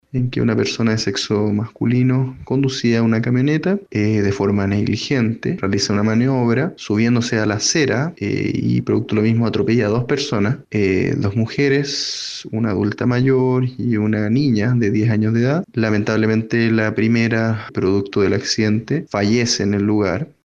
Previo a la audiencia, el fiscal de La Calera, Fernando Márquez, entregó detalles sobre la dinámica de los hechos, señalando que el vehículo involucrado se subió a la acera, atropellando a ambas víctimas.